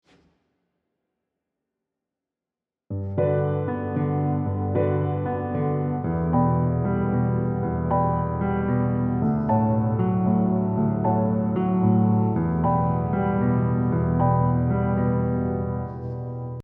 piano 3 Minutes
My progression of choice is I — iii — V — iii in G major.
Same bass line, plus — I’ve added something to keep the right hand busy.
Shell voicings are my go-to, since they have a very nice jazzy sound while being dead simple to execute, so you can focus on left hand almost entirely.
This already starts to sound interesting.